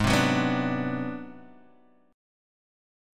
Ab7#9b5 chord